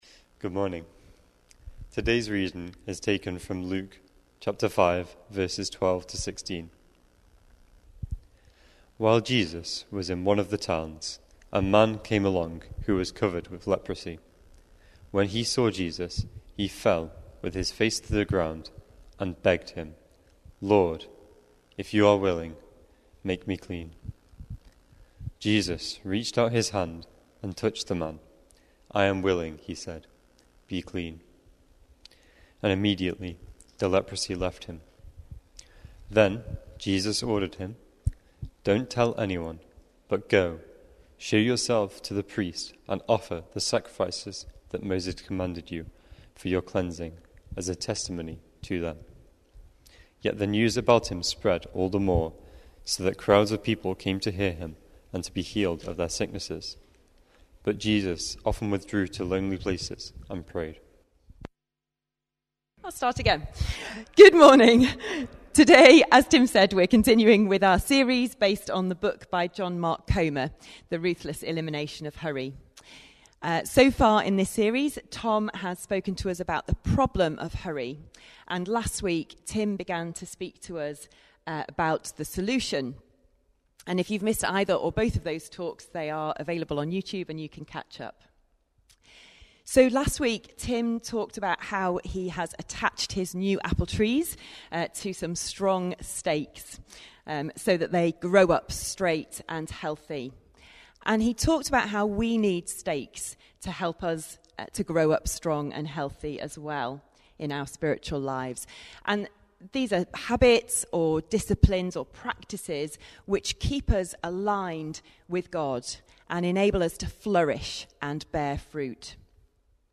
NBC-Worship-7th-March-2021.mp3